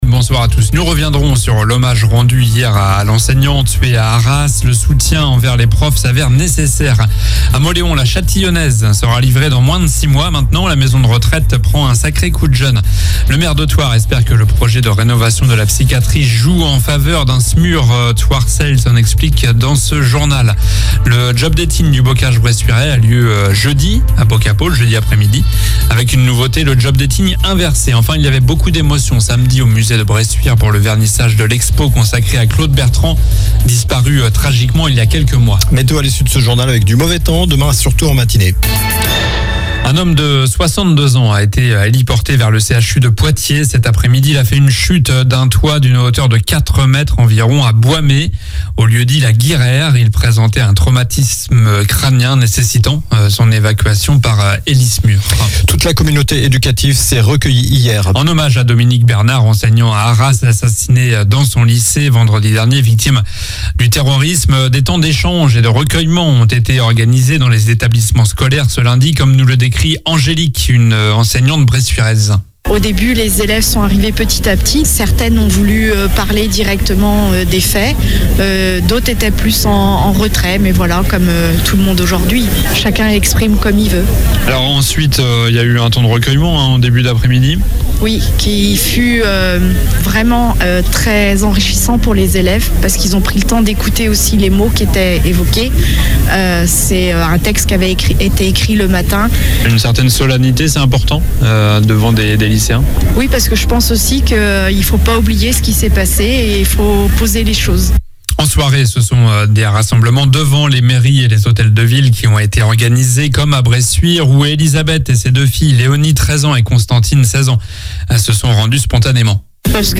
COLLINES LA RADIO : Réécoutez les flash infos et les différentes chroniques de votre radio⬦
Journal du mardi 17 octobre (soir)